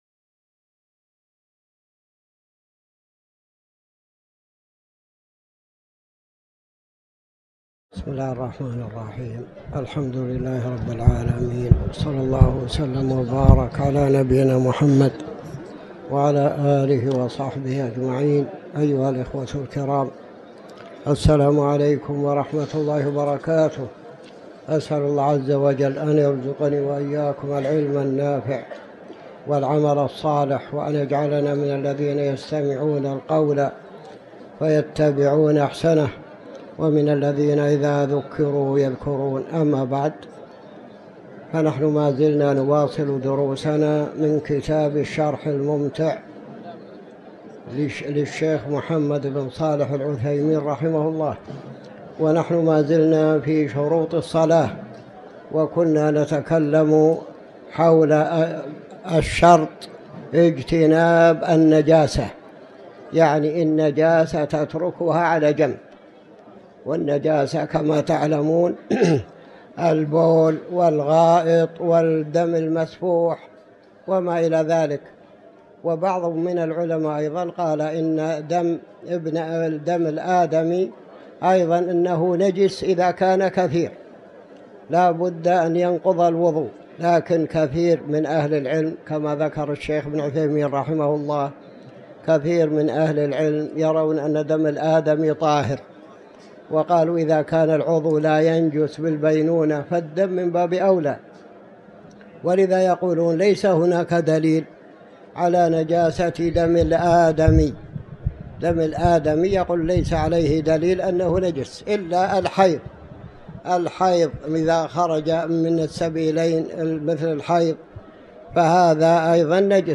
تاريخ النشر ١٥ جمادى الآخرة ١٤٤٠ هـ المكان: المسجد الحرام الشيخ